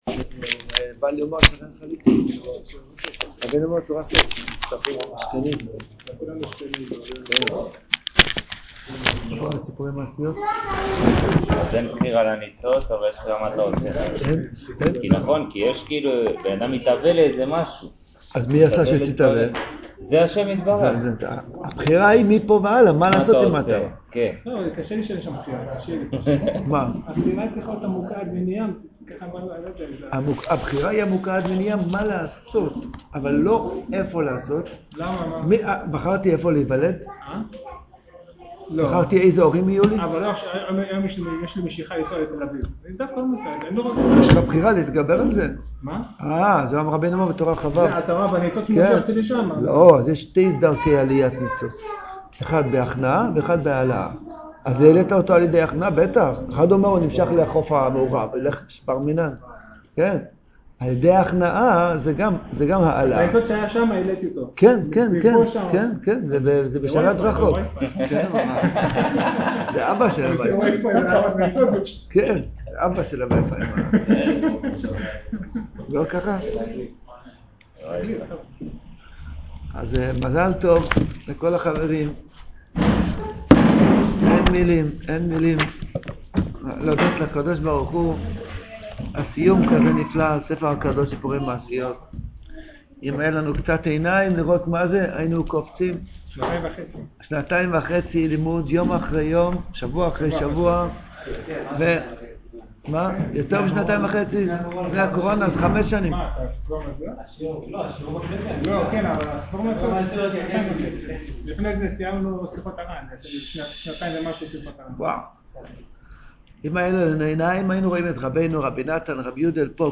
שיעור בחיפה סיום ספומע הכנה ללג בעומר ופסח שני תשפד